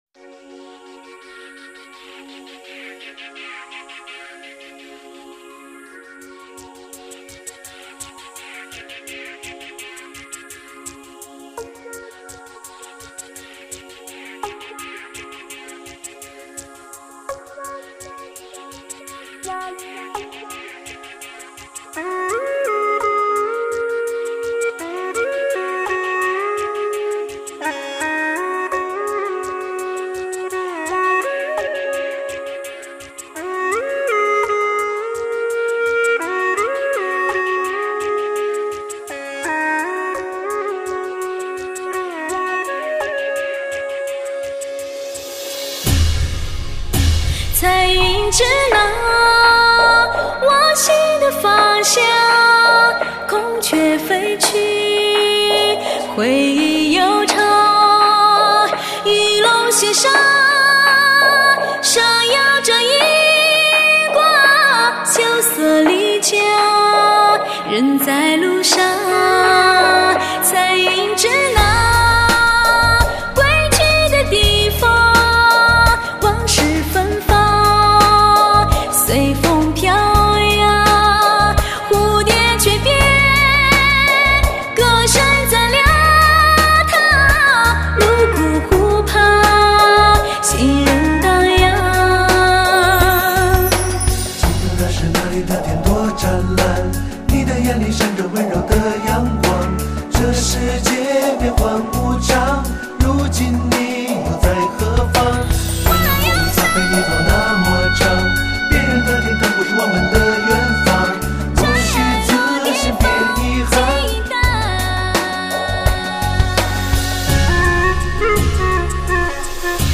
清亮脱俗，精彩绝伦的演绎，带您进入迷醉的音乐国度，
完美嗓音，真情流露，原味音效营造出最发烧人声天碟！
民族金曲发烧全新演绎，唱出音乐里流淌的激情，
新世纪迷醉曲风，顶级音乐人制作！